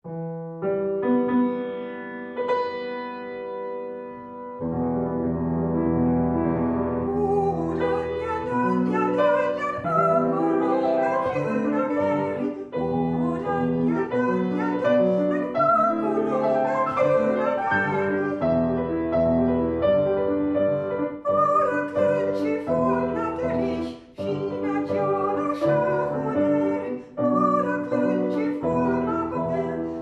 Gesang
Klavier
Seelieder